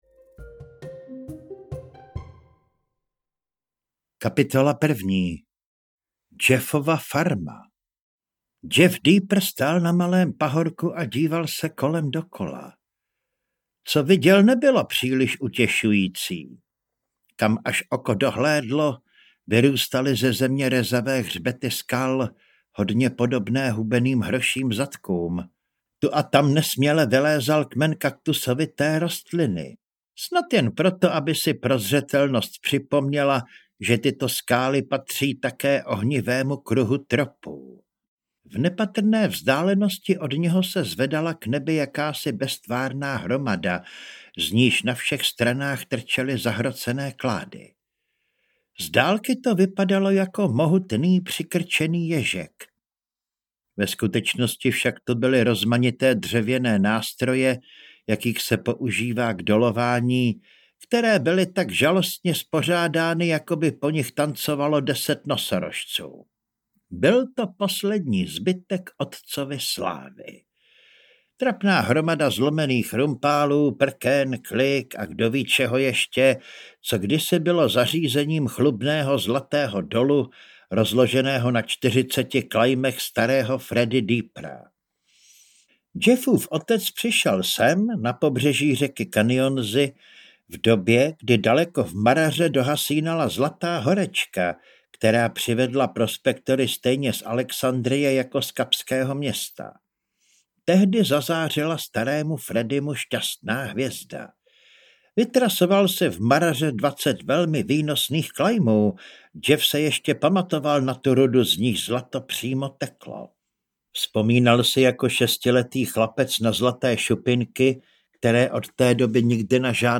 Africká sopka audiokniha
Ukázka z knihy
africka-sopka-audiokniha